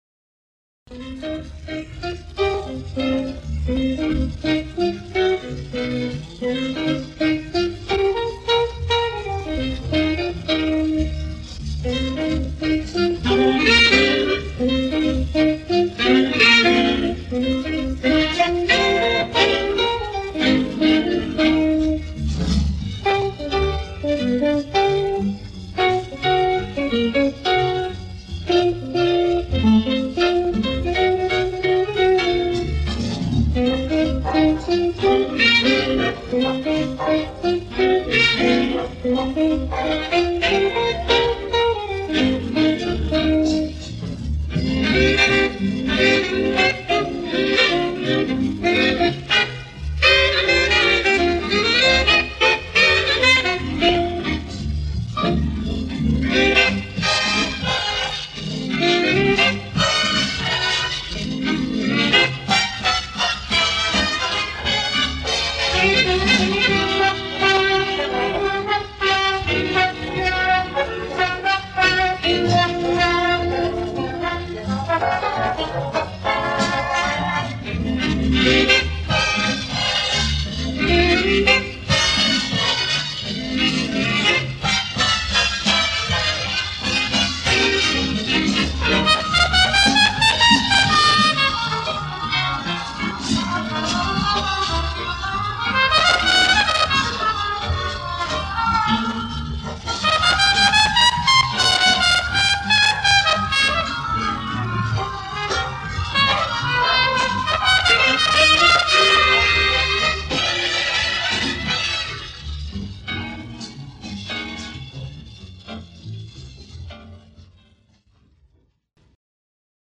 Инструментал 1